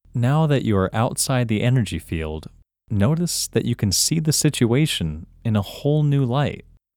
OUT – English Male 17